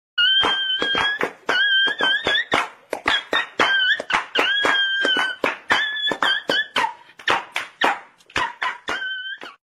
DOG DANCE Meme Effect sound effects free download